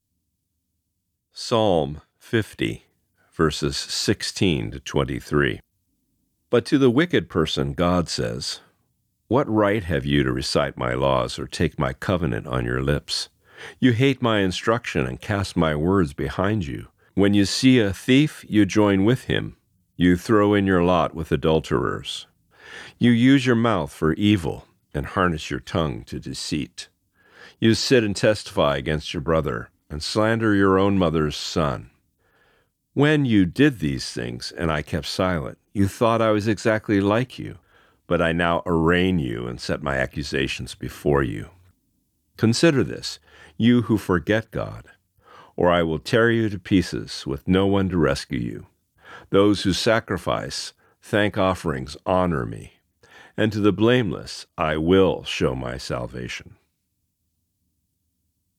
Today’s Reading: Psalm 50:16-23